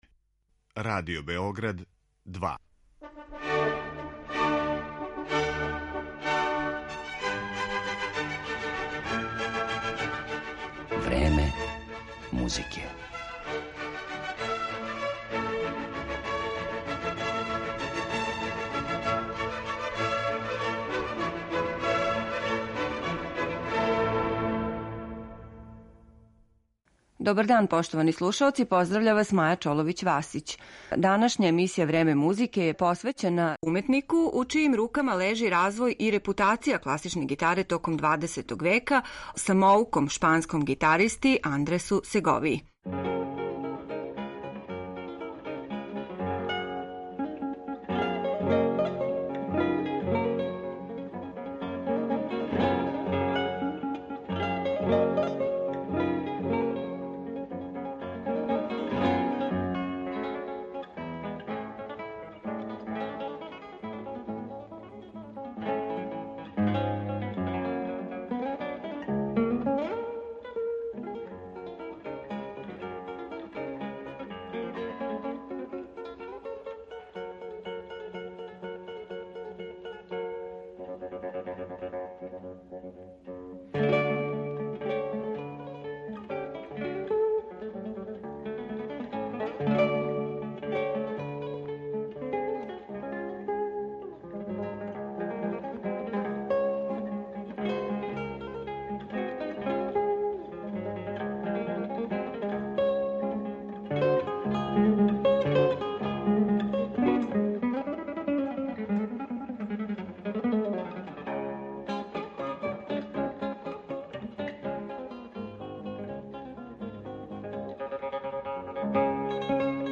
Реч је о уметнику у чијим рукама лежи развој и репутација класичне гитаре током XX века, о самоуком шпанском гитаристи Андресу Сеговији. У његовој интерепретацији слушаћете композиције Албениза, Тареге, Сора, Гранадоса и других аутора неких од најпопуларнијих остварења у гитаристичком репертоару.